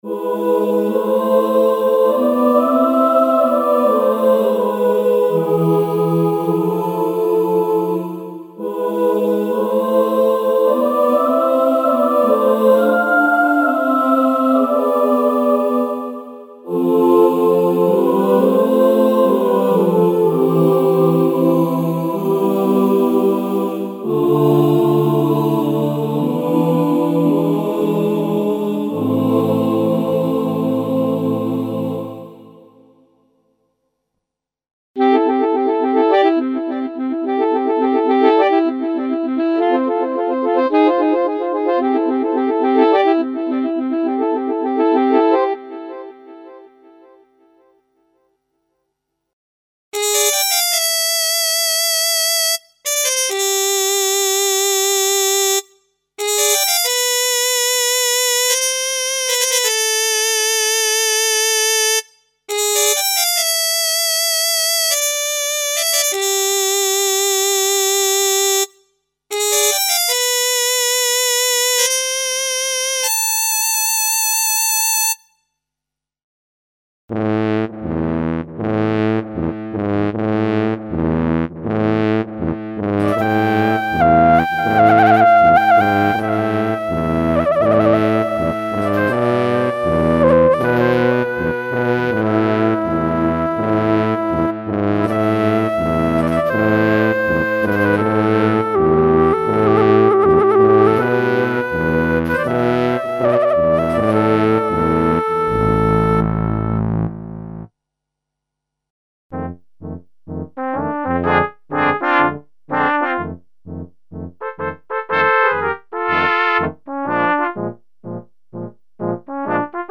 Collection of classical wind instruments (wood winds, flutes, trumpets, saxophones, brass sections, etc.) and voices, including selection of program layer & split combinations for live performance.
Info: All original K:Works sound programs use internal Kurzweil K2661 ROM samples exclusively, there are no external samples used.